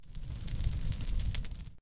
fire.ogg